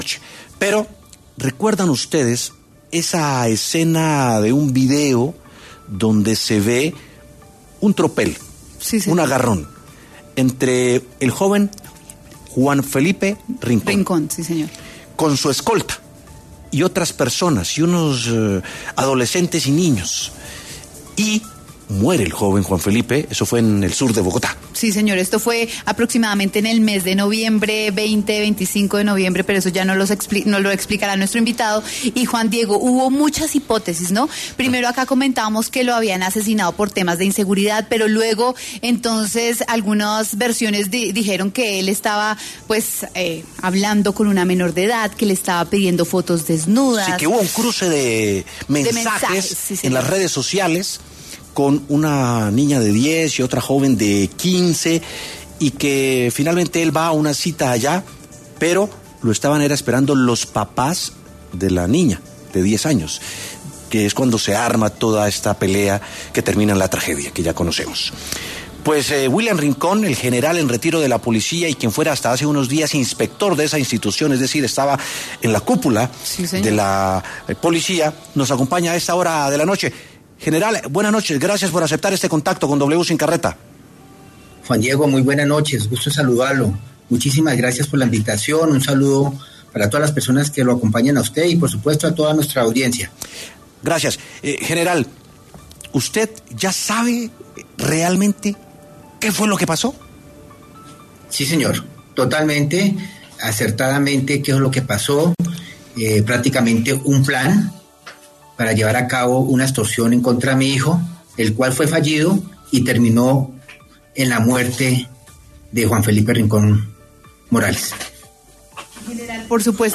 En entrevista con W Sin Carreta